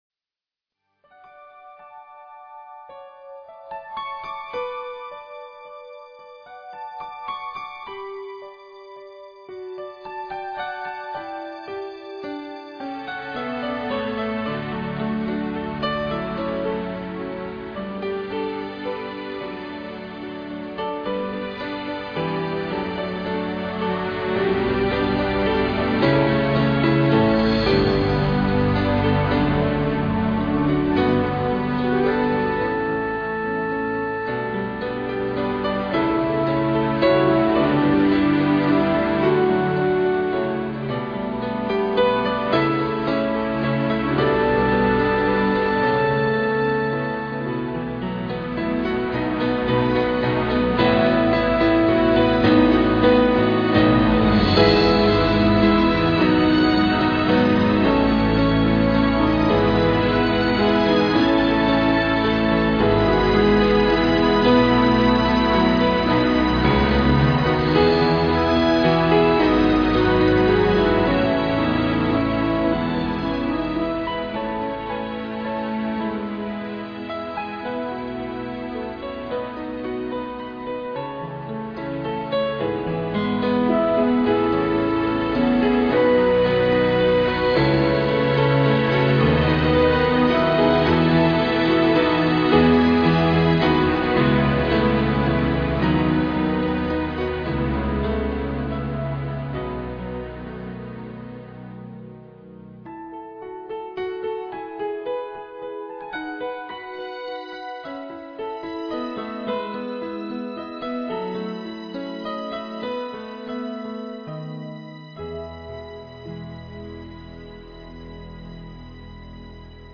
Message
duet